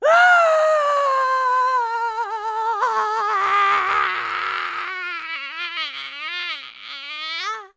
traf_longscream.wav